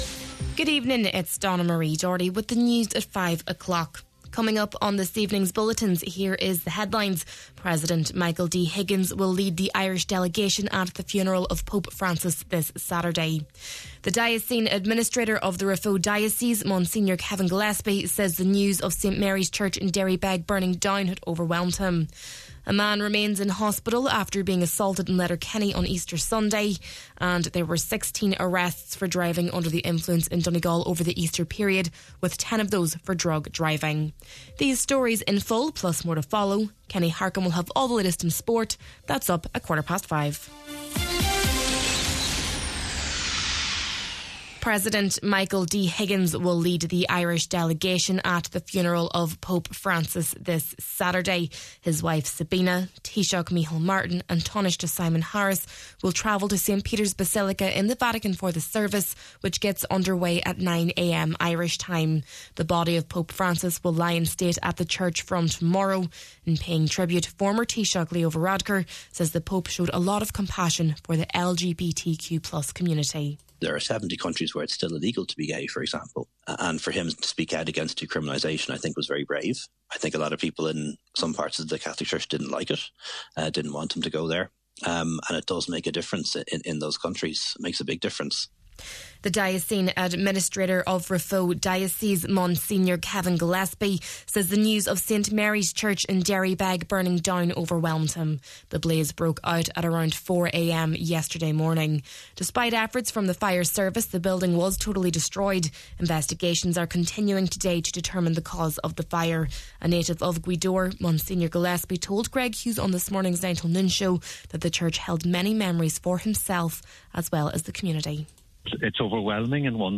Main Evening News, Sport and Obituaries – Tuesday, April 22nd